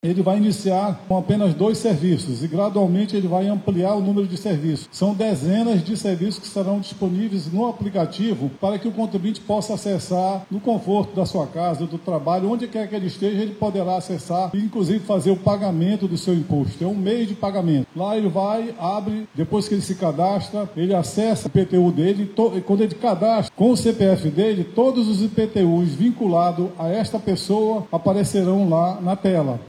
Com isso, a plataforma facilita a emissão de guias de pagamento, dispensando a necessidade de deslocamento a unidades físicas da Prefeitura e o consumo de papel, como explica o sub-secretário da Secretaria Municipal de Economia e Finanças (Semef), Armínio Pontes.
Sonora-1-–-Arminio-Pontes-.mp3